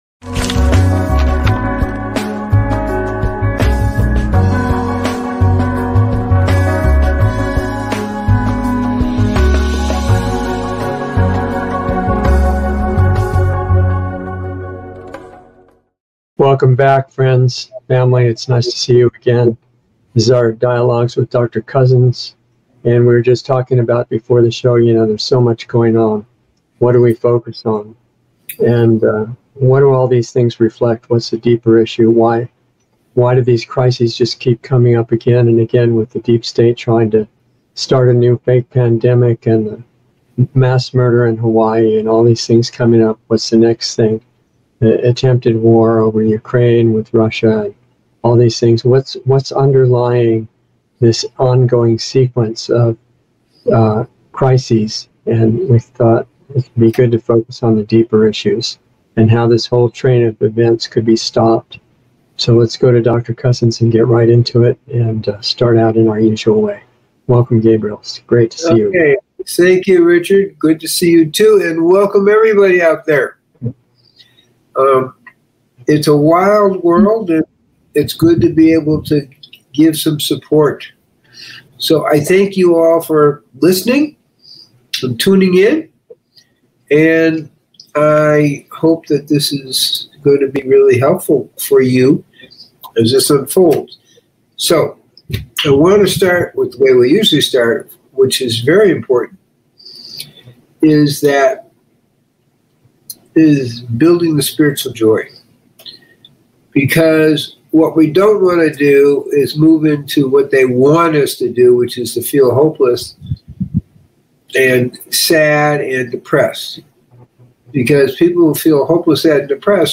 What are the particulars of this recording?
A new LIVE series